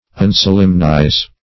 Search Result for " unsolemnize" : The Collaborative International Dictionary of English v.0.48: Unsolemnize \Un*sol"em*nize\, v. t. [1st pref. un- + solemnize.] To divest of solemnity.